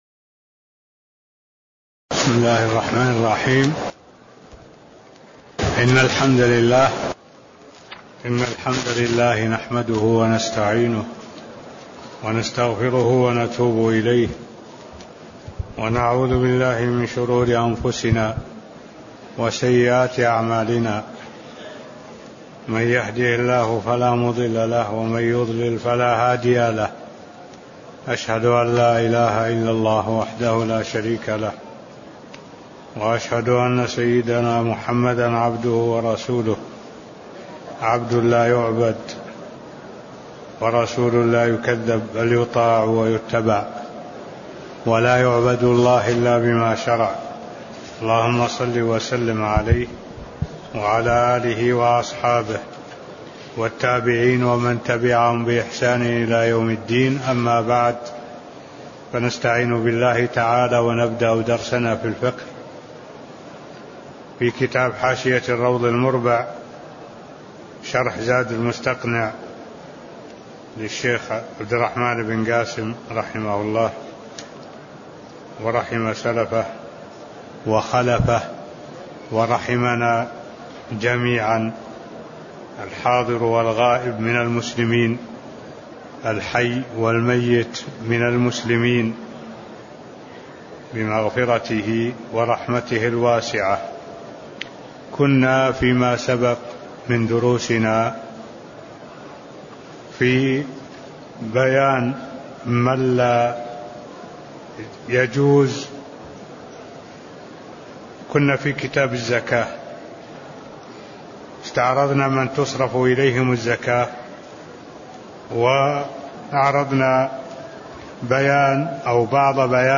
تاريخ النشر ٢٩ جمادى الأولى ١٤٢٧ هـ المكان: المسجد النبوي الشيخ: معالي الشيخ الدكتور صالح بن عبد الله العبود معالي الشيخ الدكتور صالح بن عبد الله العبود من لا تجب عليه الزكاة (009)